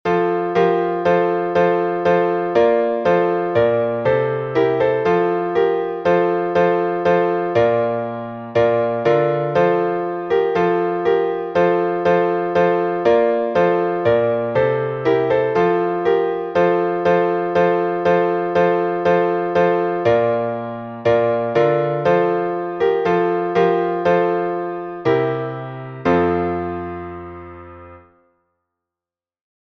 Знаменного распева
priidite_poklonimsja_znamennij.mp3